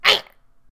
capri_die.ogg